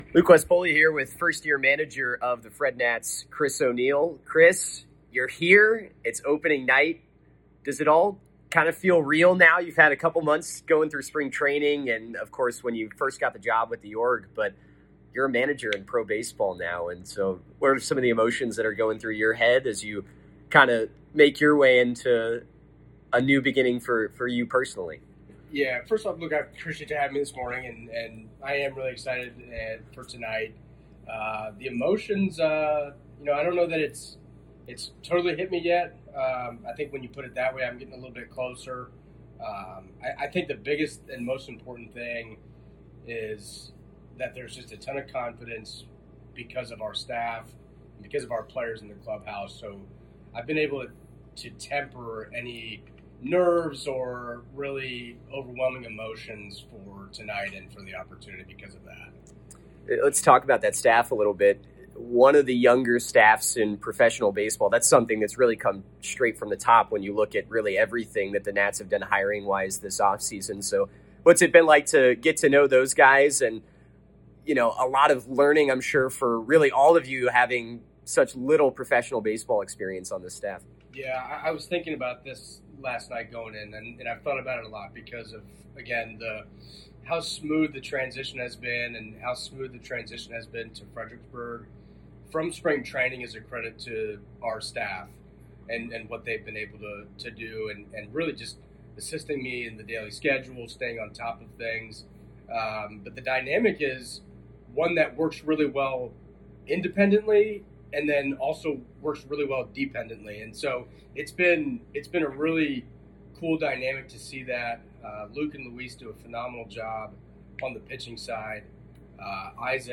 Interviews
Listen to FredNats player interviews conducted by FredNats broadcasters.